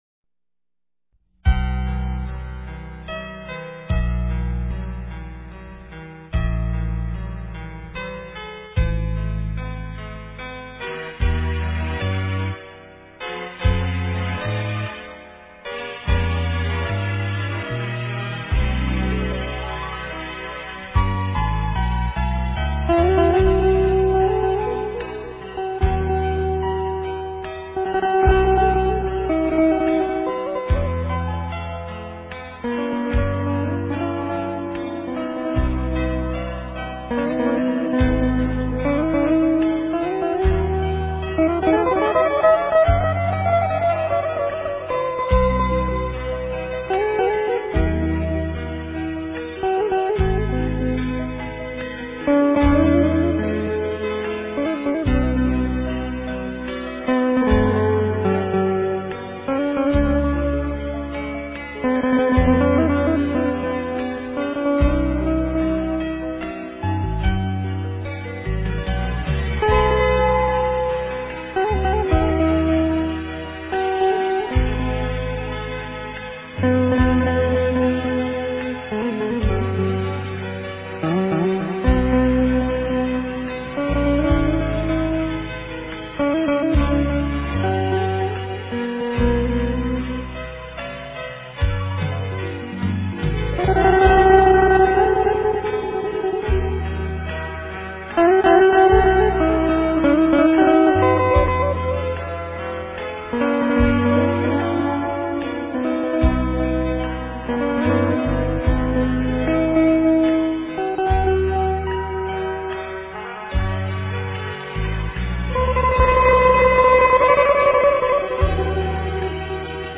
* Ca sĩ: Không lời